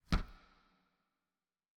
reverb_step_1.wav